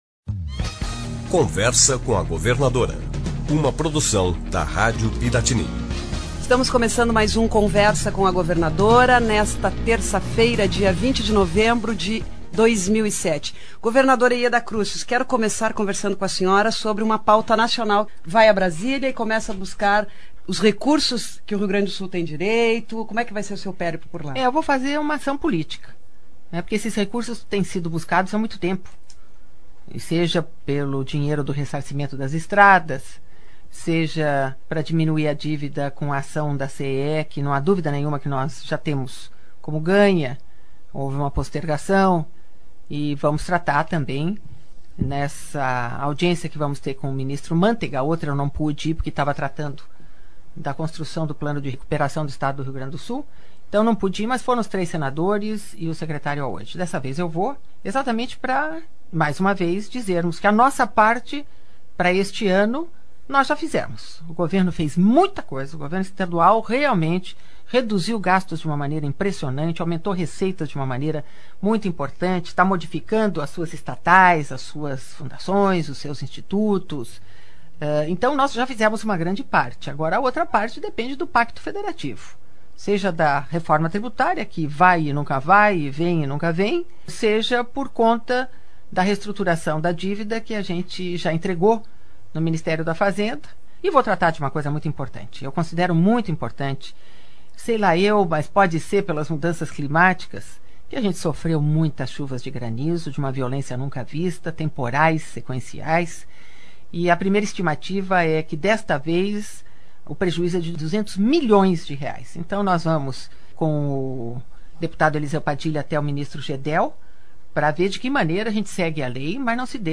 O assunto foi um dos destaques do Conversa com a Governadora. Em seu programa semanal de rádio, Yeda Crusius informou que, além de tratar sobre os créditos devidos pela União ao Estado, irá apresentar ao ministro da Fazenda todos os esforços já